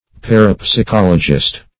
parapsychologist \par`a*psy*chol"o*gist\ n.